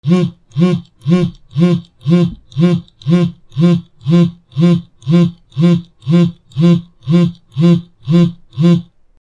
/ G｜音を出すもの / G-01 機器_電話
携帯電話 バイブ 机
ブブブ